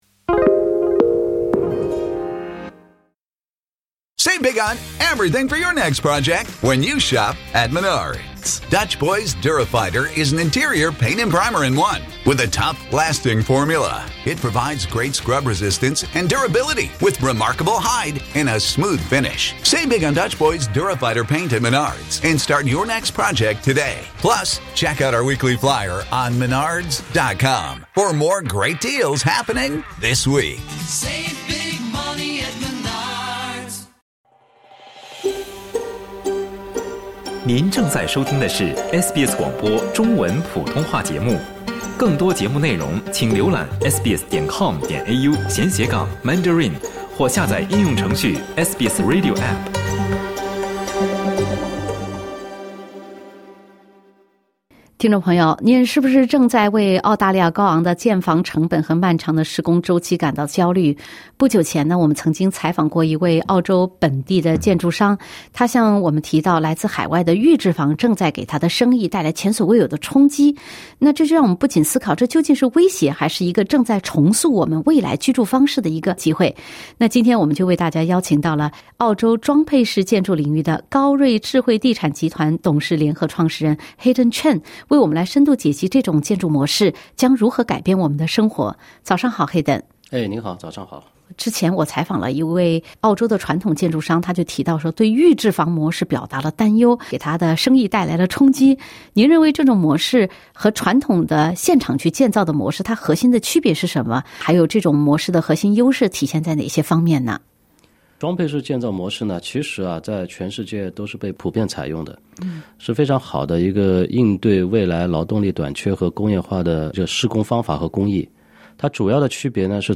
10:20 SBS 普通话电台 View Podcast Series Follow and Subscribe Apple Podcasts YouTube Spotify Download (9.46MB) Download the SBS Audio app Available on iOS and Android 中国预制房来了！